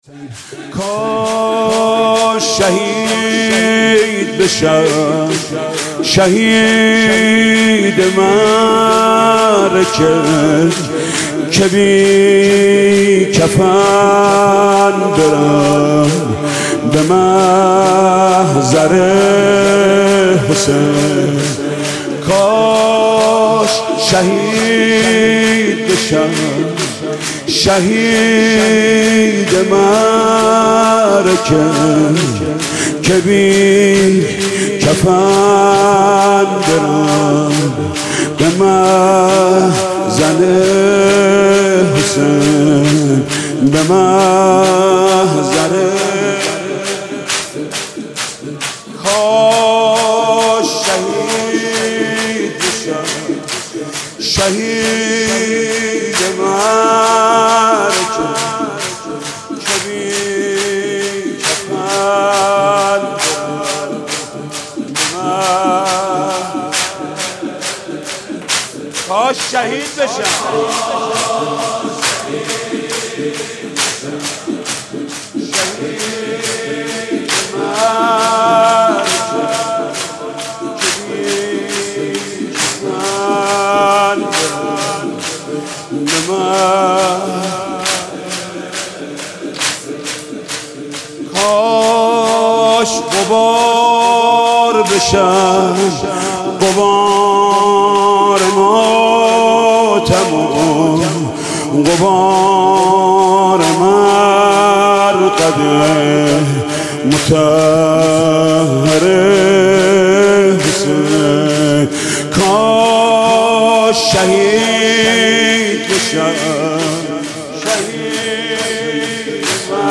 مداحی شب دوم محرم 1402 محمود کریمی